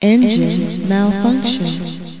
enginmf2.mp3